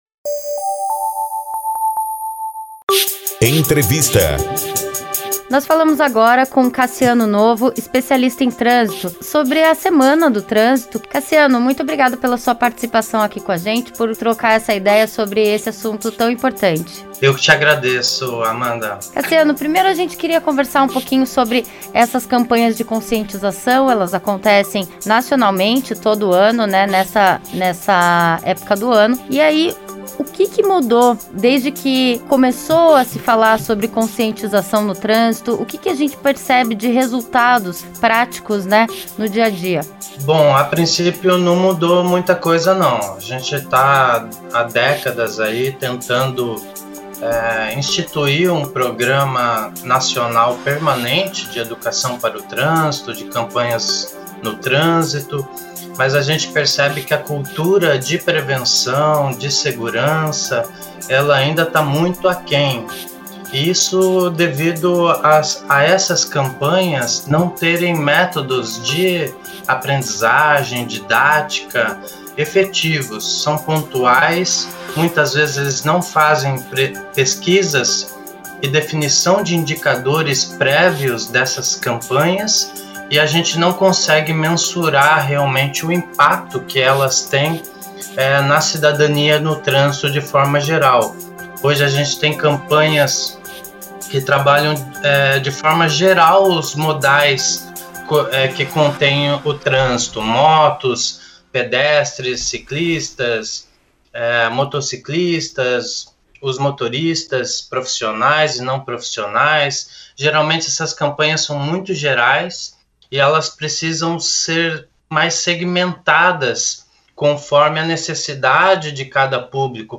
entrevista um especialista sobre os problemas na mobilidade urbana e as possíveis soluções para um trânsito melhor.